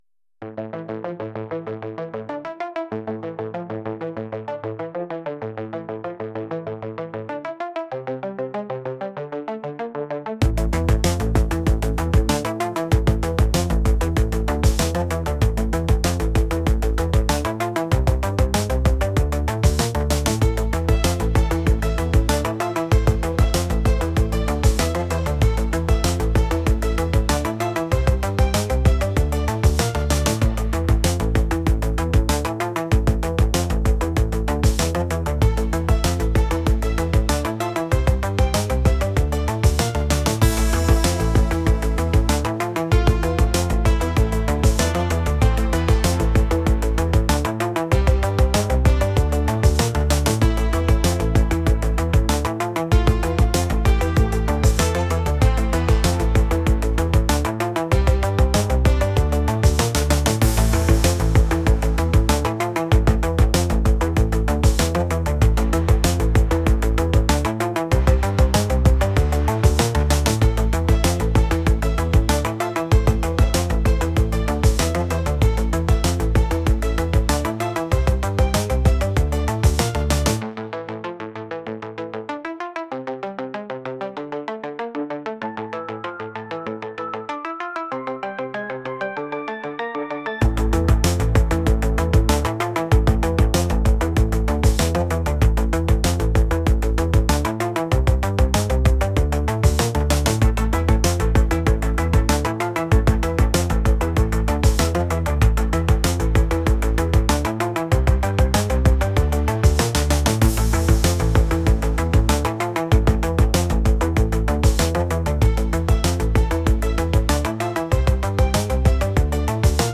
> ambient, darkwave techno, deus ex soundtrack style, demoscene